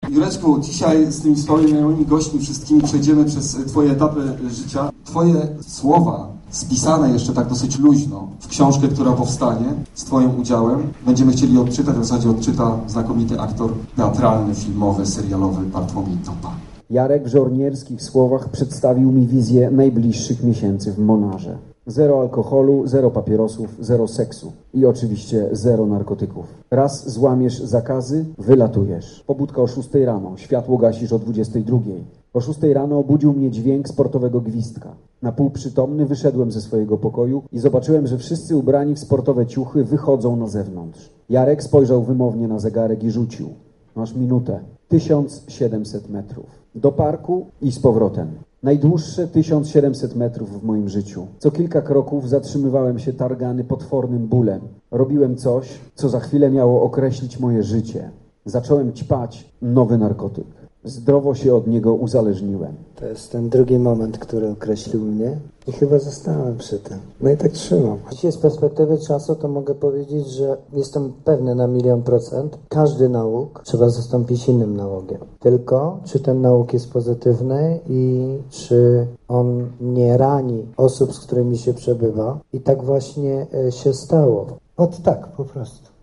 czytał aktor Bartłomiej Topa. Mowa była o trudnych latach młodości i narkomanii, o ośrodku Monaru i wychodzeniu z nałogu, a na koniec o nowej pasji i zwycięstwie w podwójnym Ironmanie.